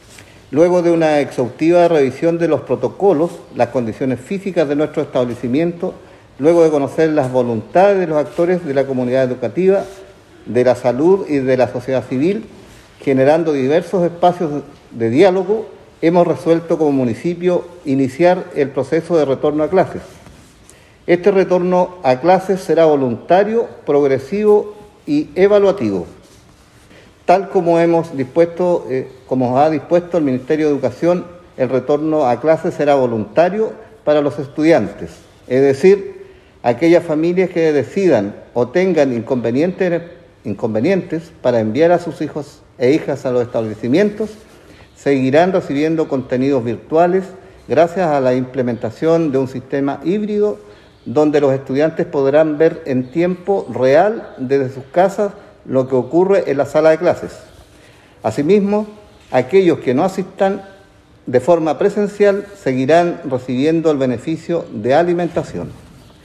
Así lo informó en conferencia de prensa el Alcalde de la comuna de Lanco, Juan Rocha Aguilera, quien detalló que “luego de una exhaustiva revisión de los protocolos, las condiciones físicas de nuestros establecimientos y tras conocer las voluntades de los actores de la comunidad educativa, de la salud y de la sociedad civil, por medio de diversos espacios de diálogo, hemos resuelto como Municipio, iniciar el proceso de retorno a clases”.
alcalde-Juan-Rocha-Retorno-a-Clases.mp3